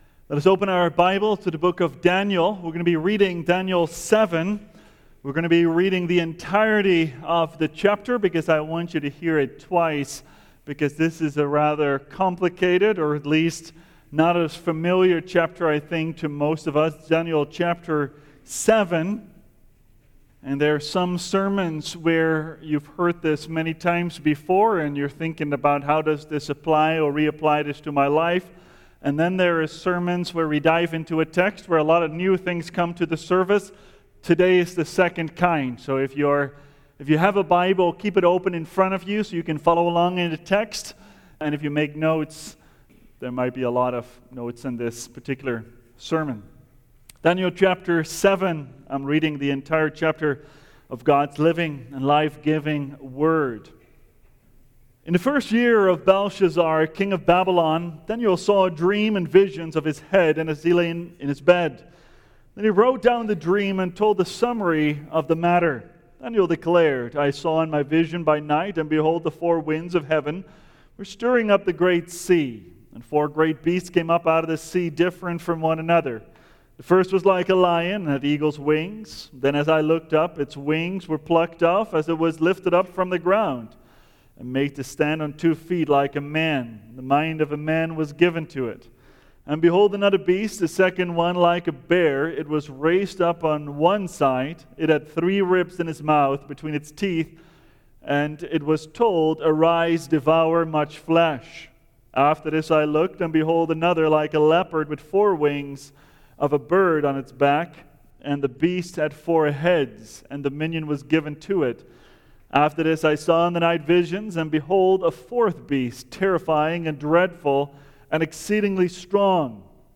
The Son of Man, the Saints, and the Everlasting Kingdom – Seventh Reformed Church